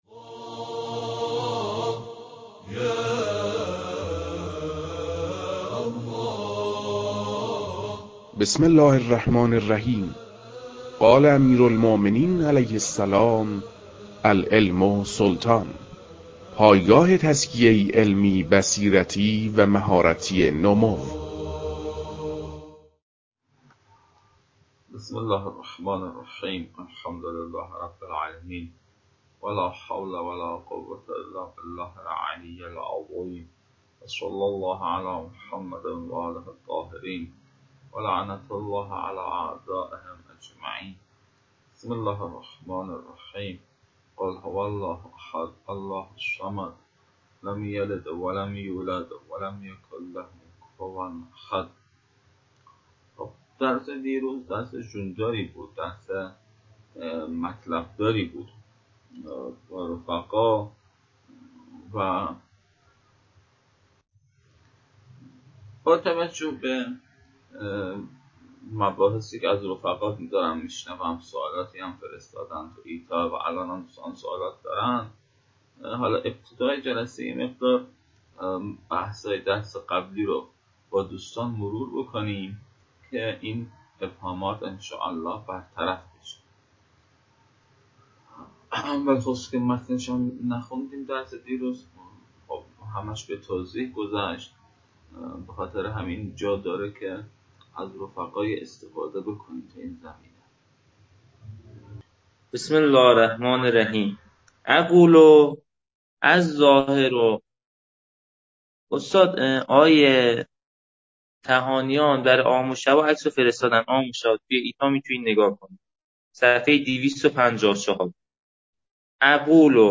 محتوای این فایل، متن‌خوانی درس پیشین است.